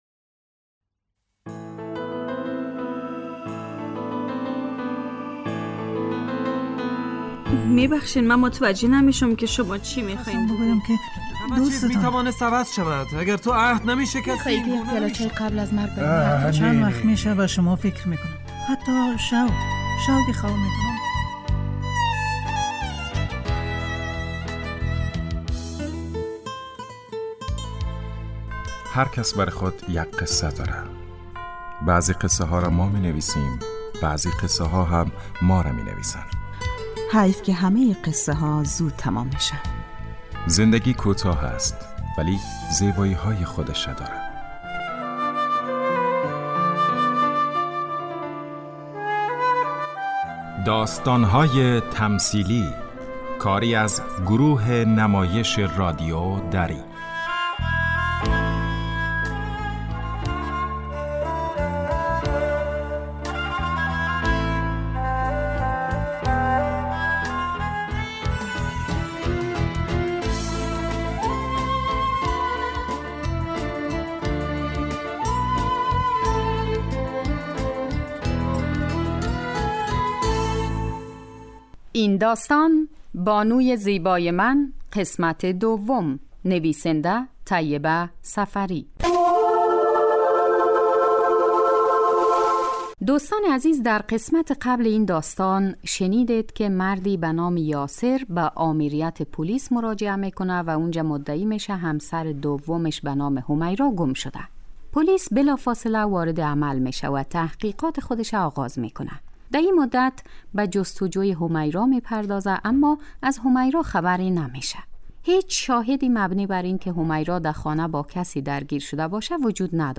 داستان تمثیلی / بانوی زیبای من